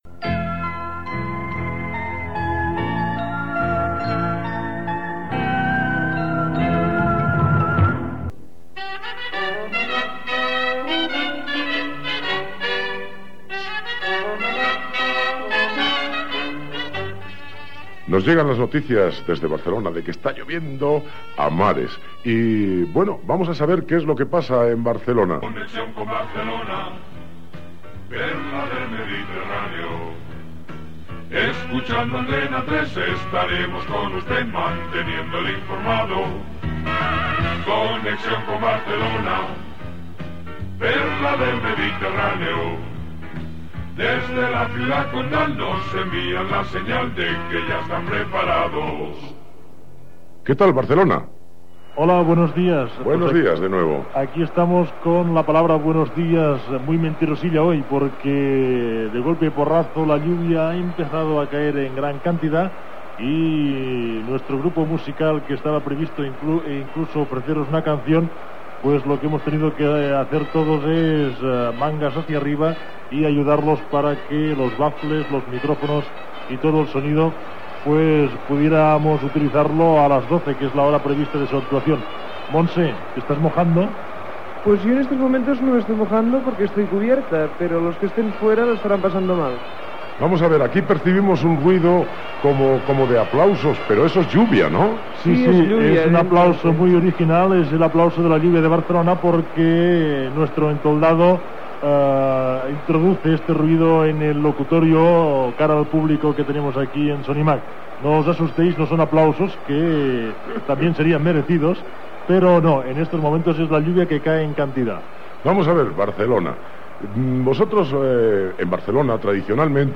Connexió amb l'estudi d'Antena 3 a la fira Sonimag.
Entreteniment
Tercer dia d'emissió d'Antena 3 de Barcelona des del Sonimag.